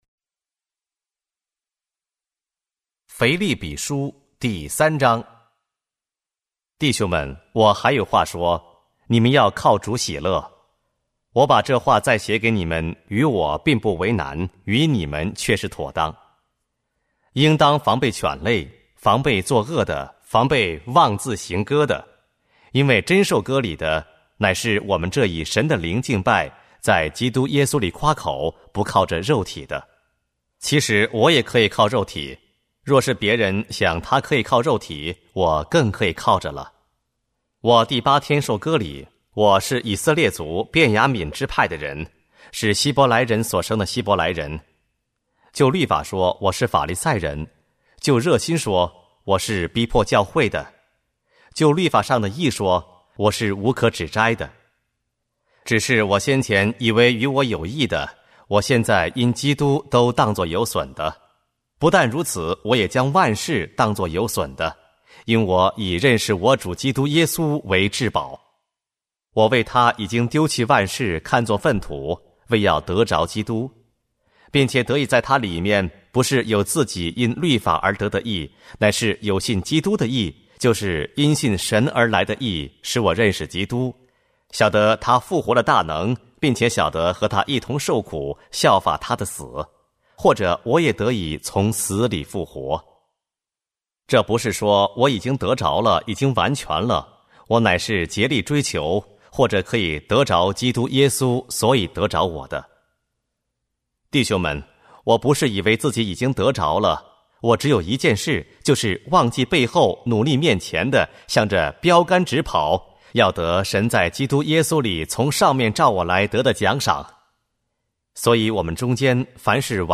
和合本朗读：腓立比书